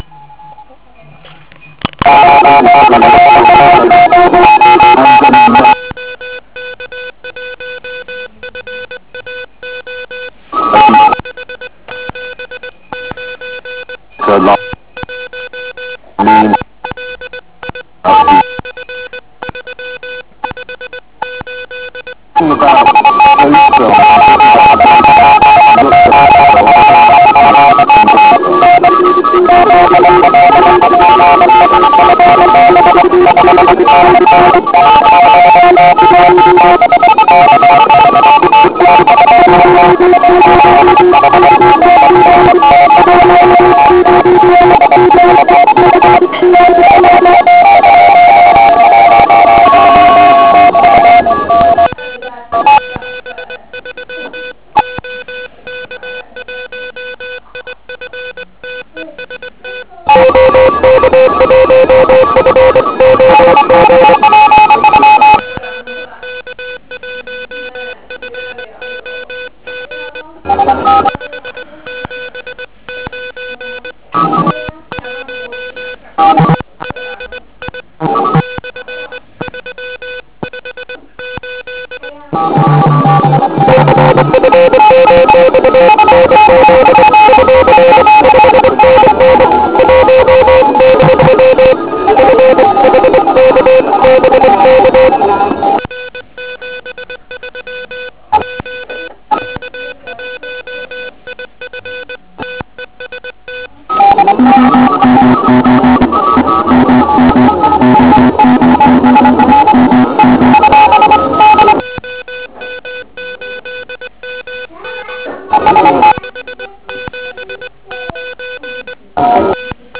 ukázka z OK QRP závodu 2007